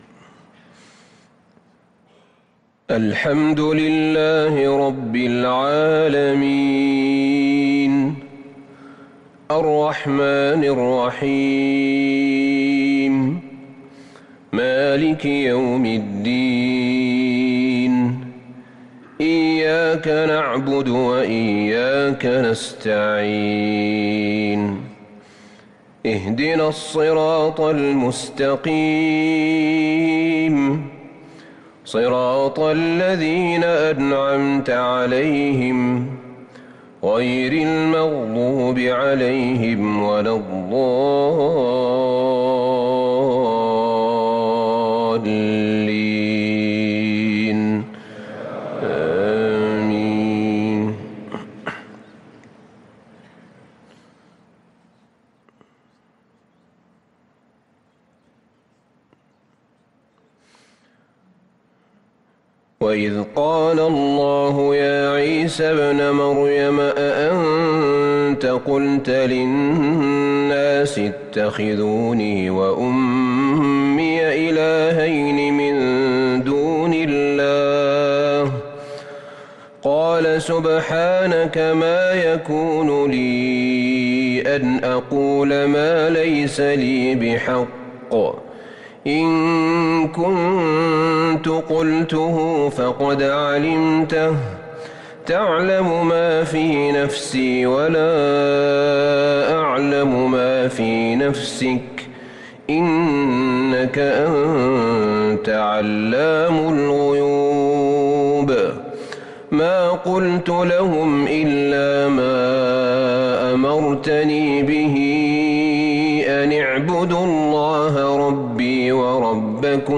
صلاة المغرب للقارئ أحمد بن طالب حميد 23 ذو القعدة 1443 هـ
تِلَاوَات الْحَرَمَيْن .